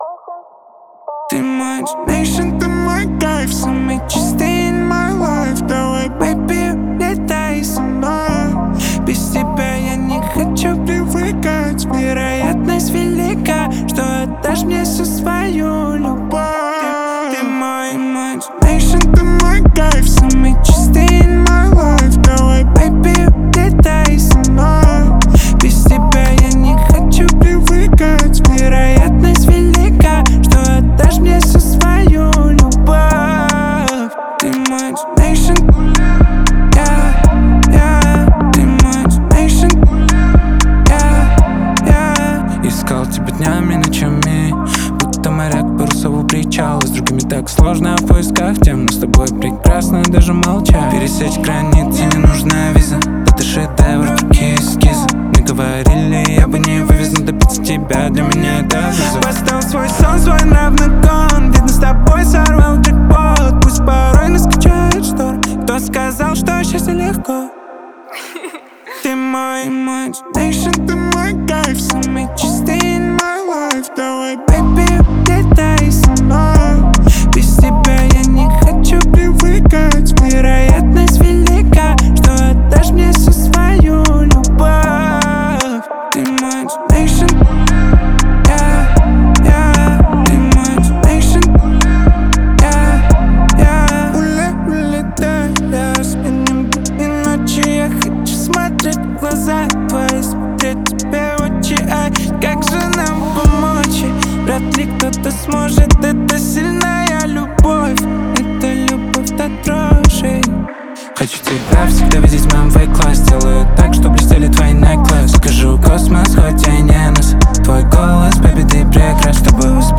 Драм-н-басс